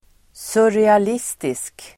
Ladda ner uttalet
Folkets service: surrealistisk surrealistisk adjektiv, surrealist , surrealistic Uttal: [sureal'is:tisk] Böjningar: surrealistiskt, surrealistiska Synonymer: overklig Definition: som återger drömmar o dyl